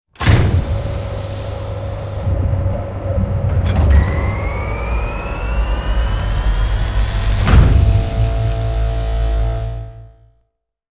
PowerOn.wav